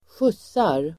Uttal: [²sjus:ar el. ²sj'uts:ar]